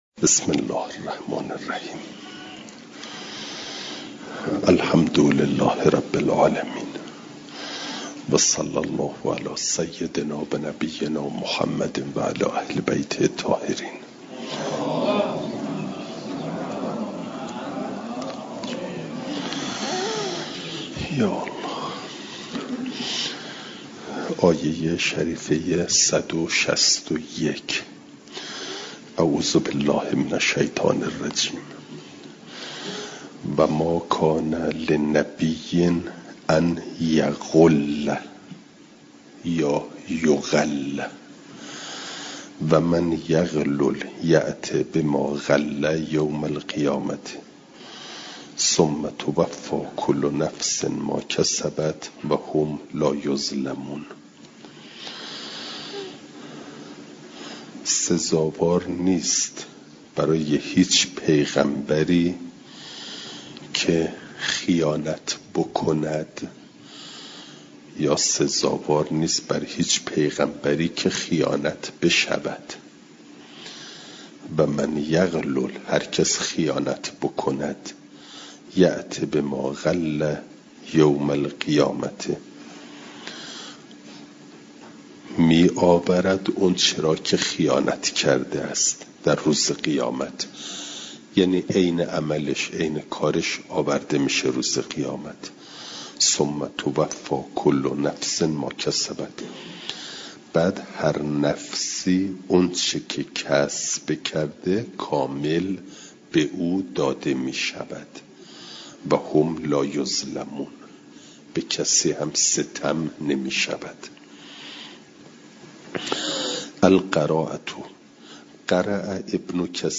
جلسه سیصد و بیستم درس تفسیر مجمع البیان